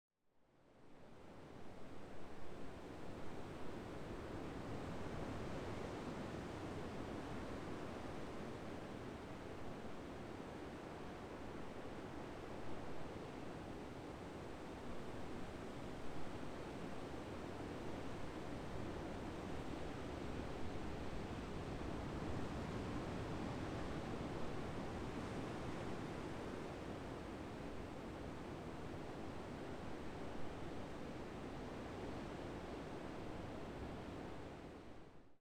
First, there is the ambient sound of the sea, and then according to the different forms of the waves, several audios reflecting different tidal states are switched according to the gravity of sun and moon.
• Ambient sea:
Sea-ambient.wav